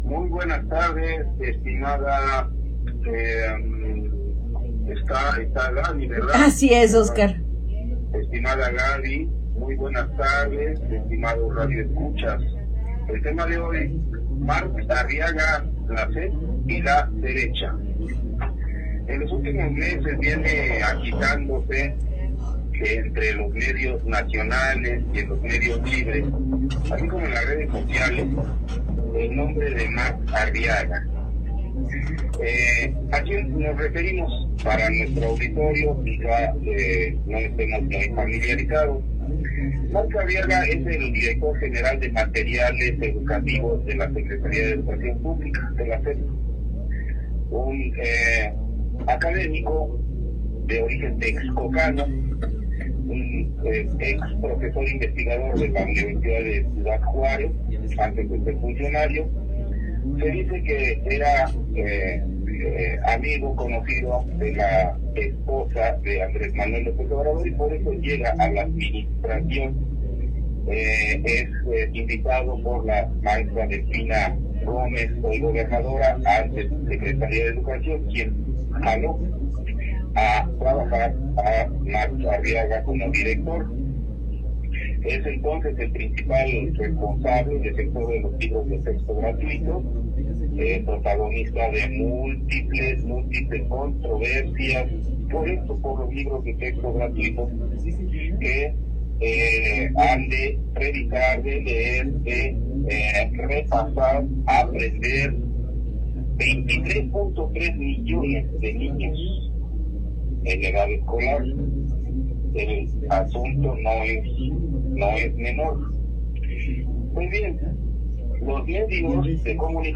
en Neza Radio